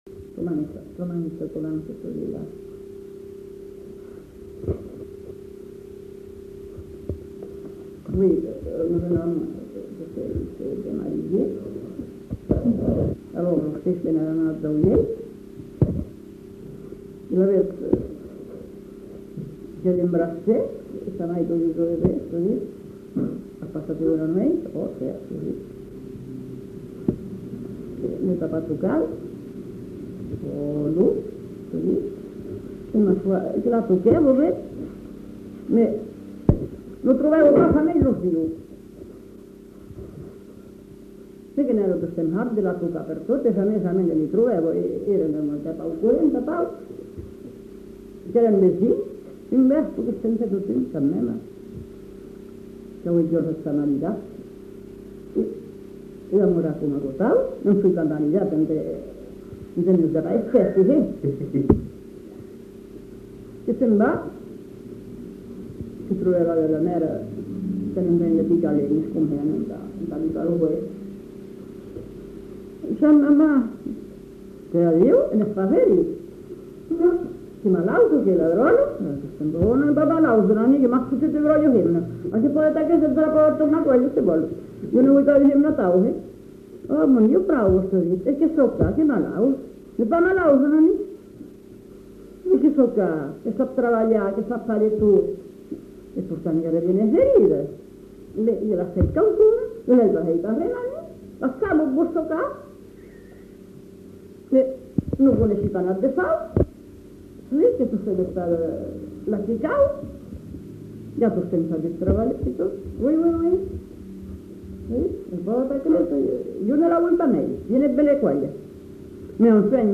Genre : conte-légende-récit
Effectif : 1
Type de voix : voix de femme
Production du son : parlé